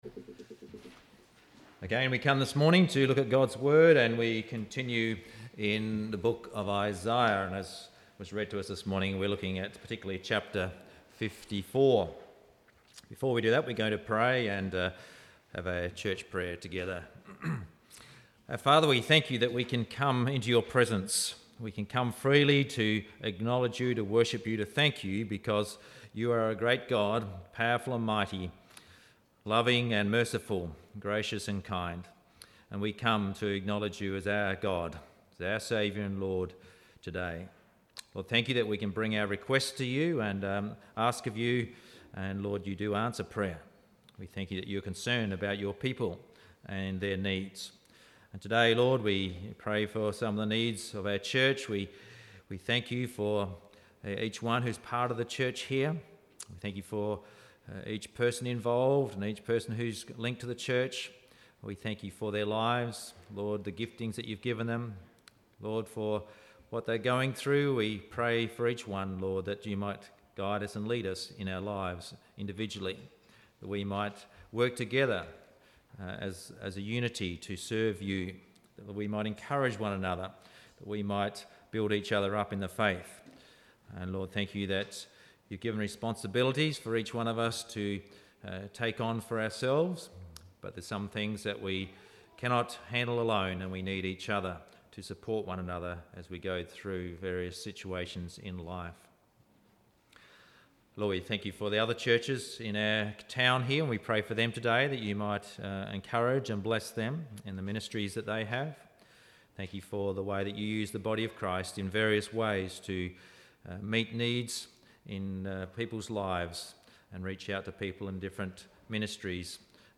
Sunday Service Audio from 11/03/18